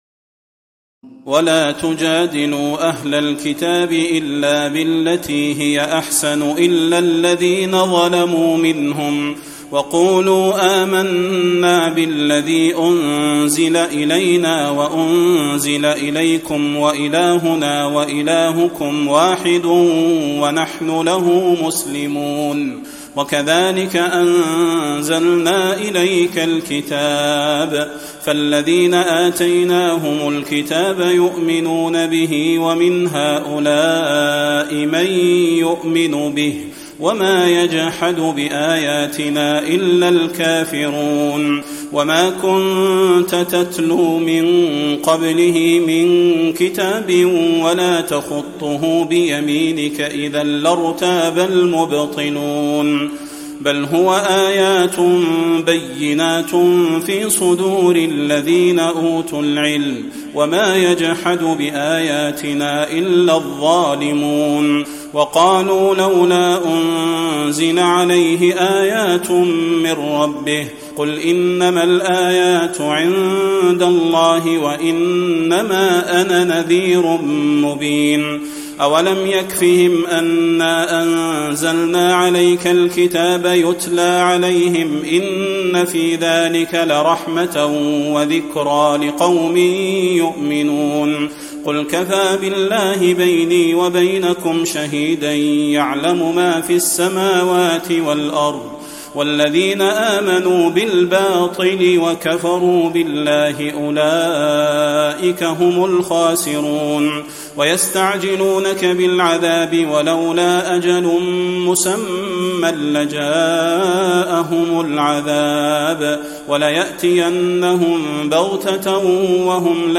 تراويح الليلة العشرون رمضان 1435هـ من سور العنكبوت (46-69) و الروم و لقمان (1-21) Taraweeh 20 st night Ramadan 1435H from Surah Al-Ankaboot and Ar-Room and Luqman > تراويح الحرم النبوي عام 1435 🕌 > التراويح - تلاوات الحرمين